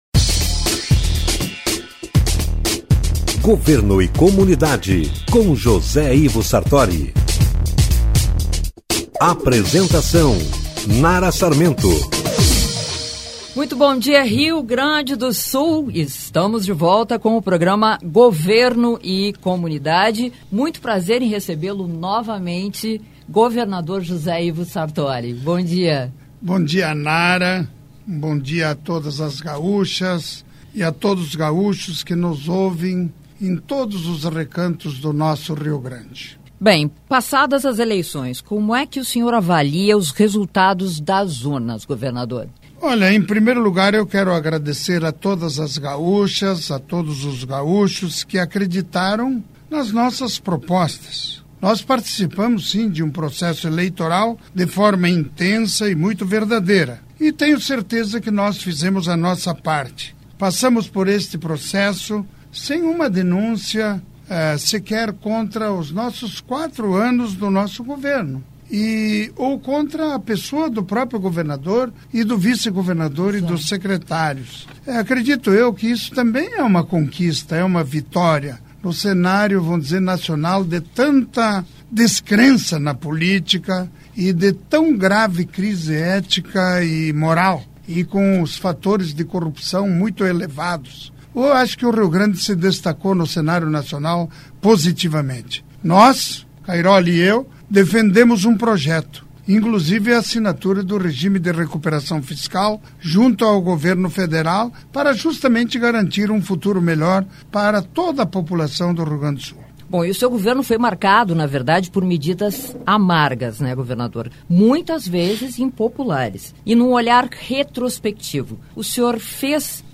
PORTO ALEGRE, RS, BRASIL 08.11.2018: O governador José Ivo Sartori participou na tarde desta quinta-feira, 8, da gravação do programa Governo e Comunidade, da Rádio Piratini.